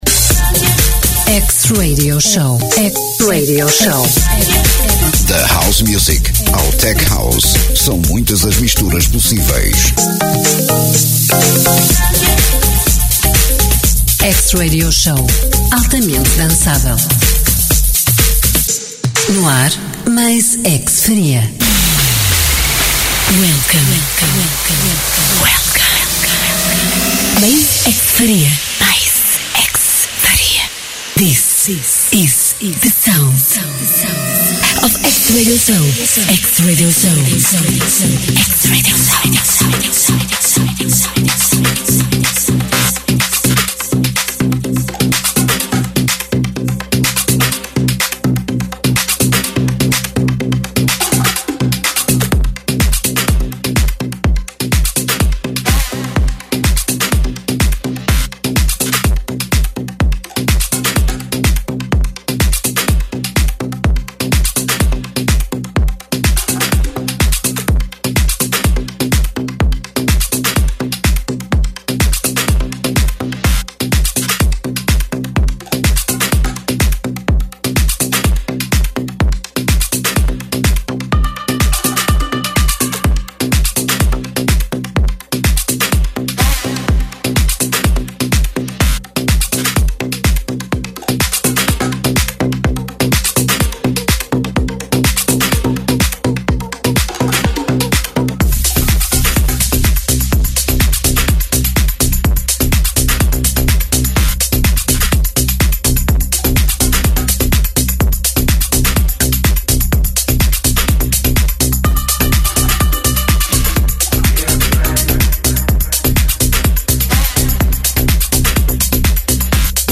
House Music in the mix